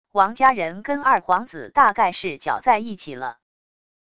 王家人跟二皇子大概是搅在一起了wav音频生成系统WAV Audio Player